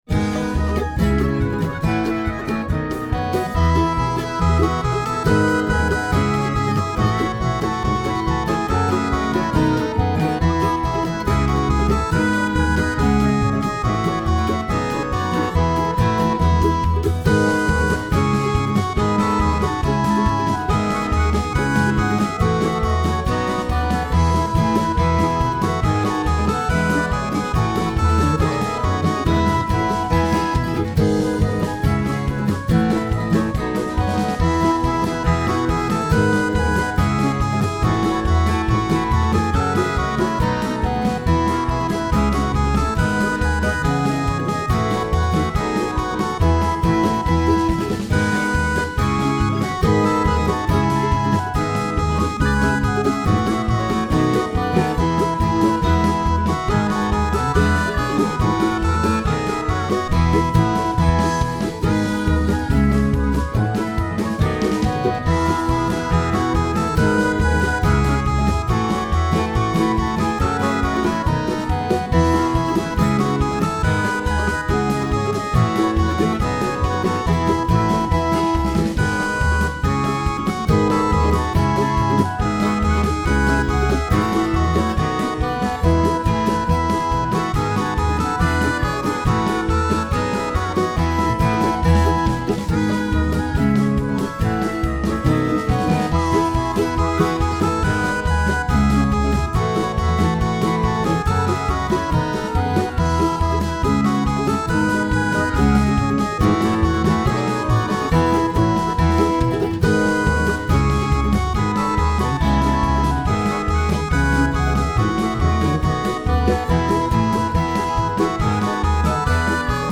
This is a lively version.